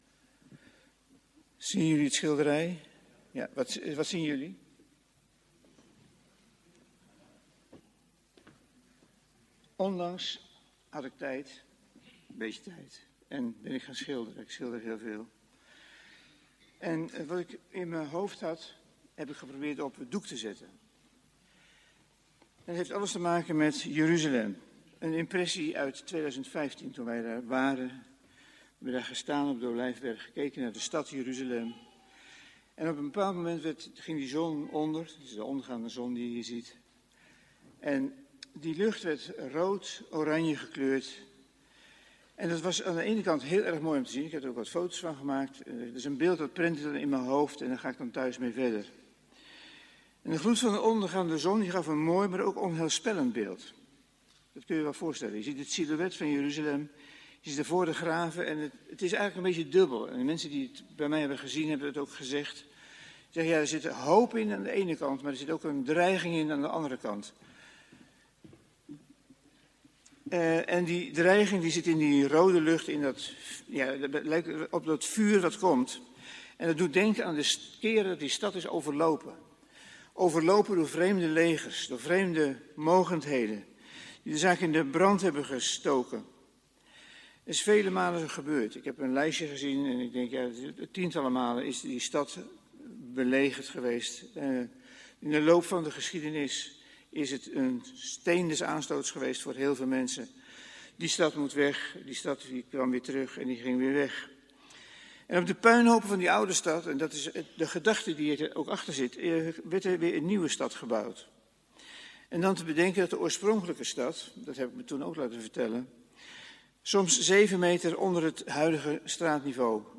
Luister hier gratis 200+ audio-opnames van preken tijdens onze evangelische diensten en blijf verbonden met Jezus!